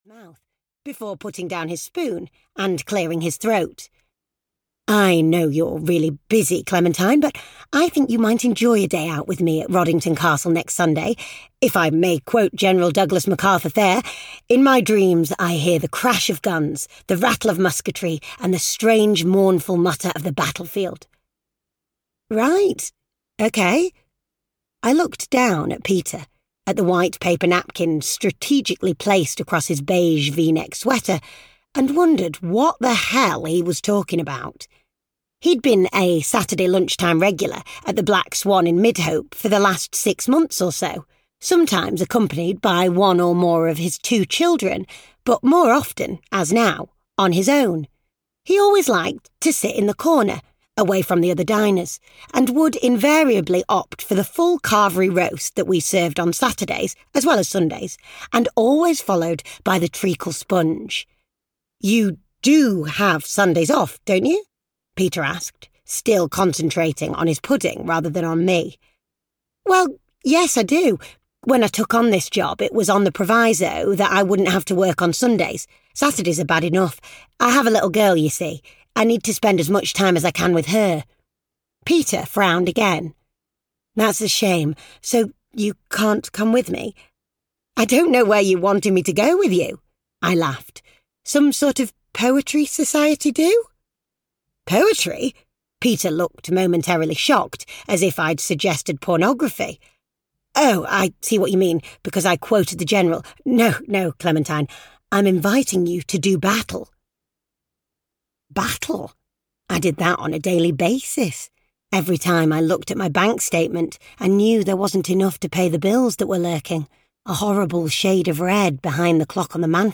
Looking for Lucy (EN) audiokniha
Ukázka z knihy